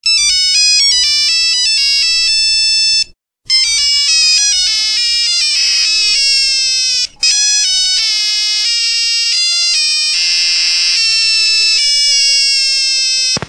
Nokia Funny Tone